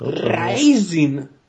– Der RIZIN-Klingelton